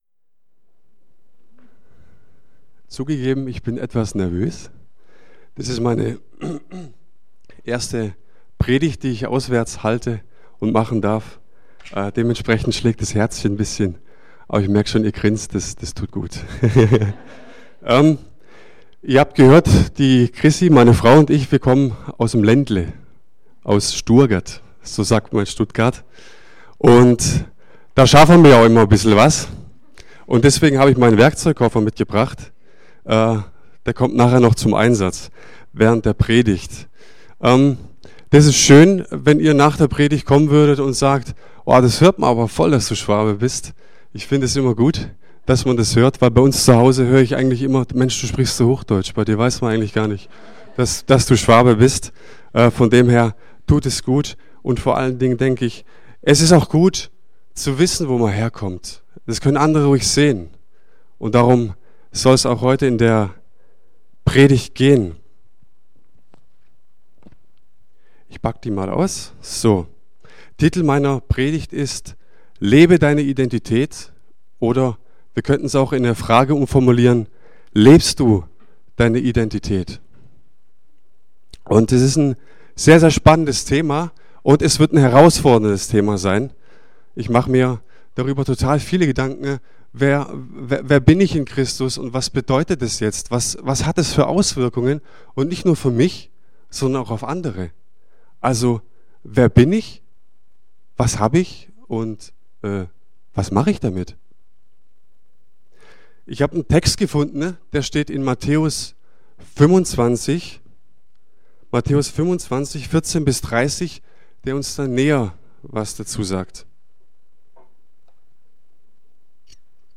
| Marburger Predigten